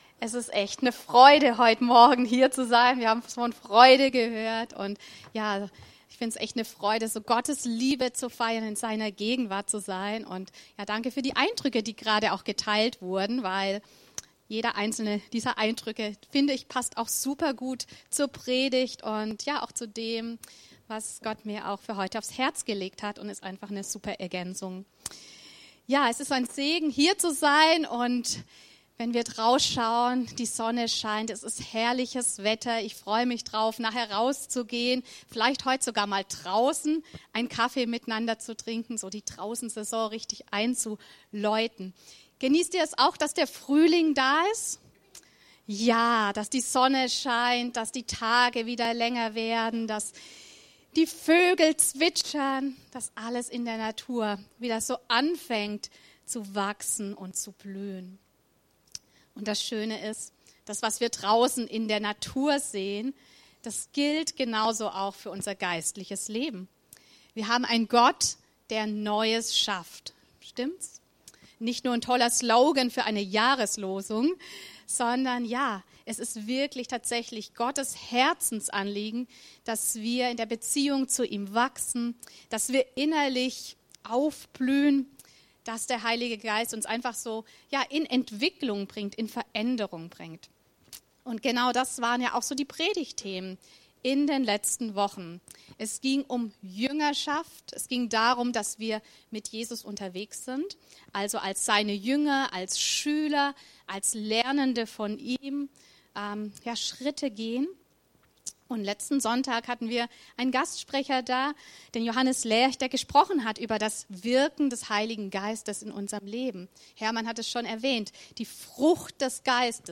Aktuelle Predigten aus unseren Gottesdiensten und Veranstaltungen